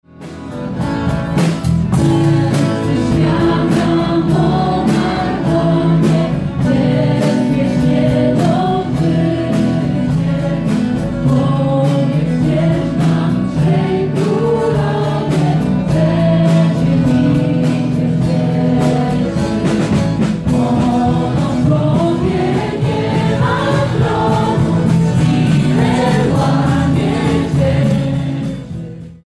Po raz 13. ulicami Bielska-Białej przeszedł Orszak Trzech Króli.
Niech ta świętość będzie codziennością. Wtedy możemy naprawdę wielkich rzeczy dokonywać – mówił bp Piotr Greger, biskup pomocniczy diecezji bielsko-żywieckiej.
– To wydarzenie – dla nas wierzących – religijne, ale też dla wszystkich wydarzenie społeczne, które cieszy się dużym zainteresowaniem – mówił z kolei Jarosław Klimaszewski, prezydent Bielska-Białej.